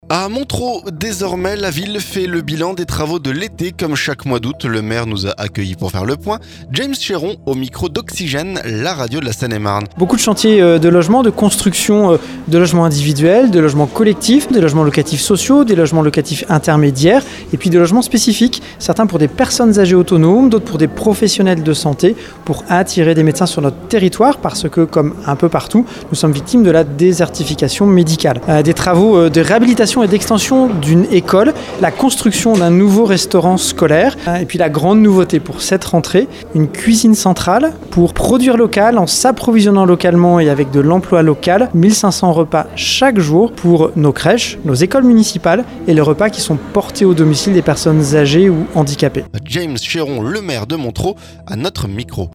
Jamaes Chéron au micro d'Oxygène, la radio de la Seine-et-Marne.